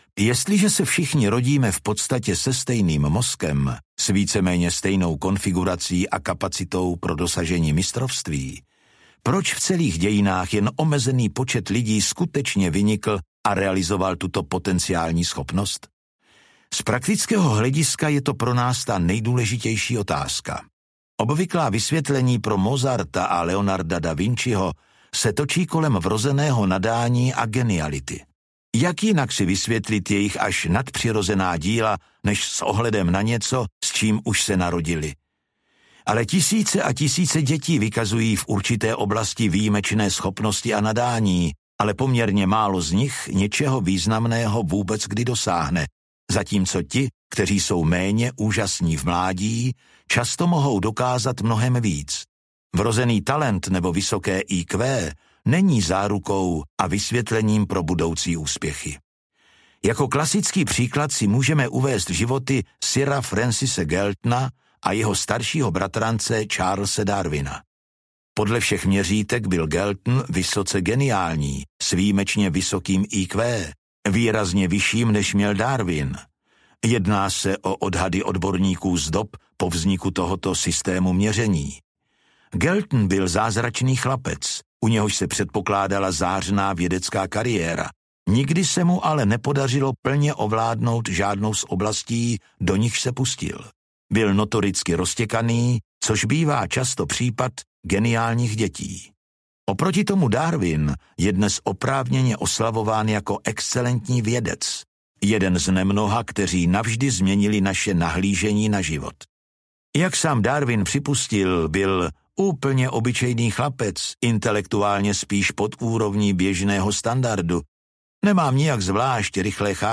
Audiokniha Mistrovství - Robert Greene | ProgresGuru